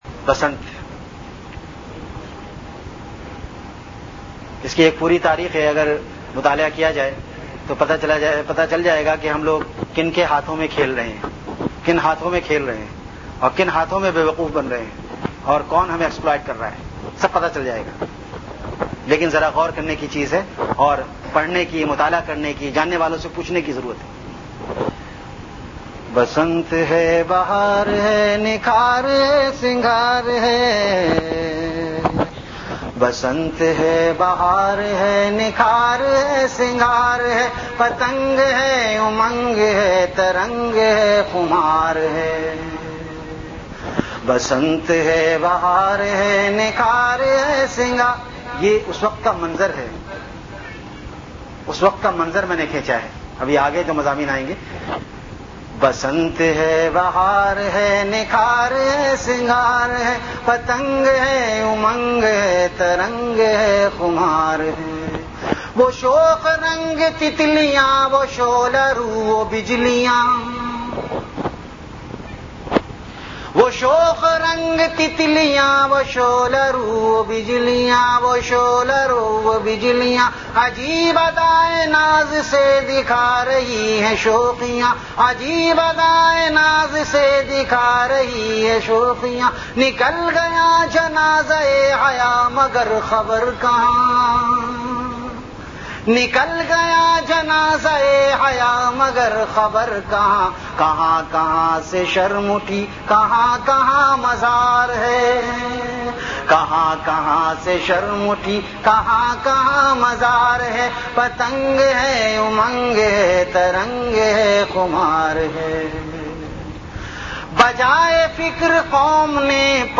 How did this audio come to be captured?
Event / TimeAfter Isha Prayer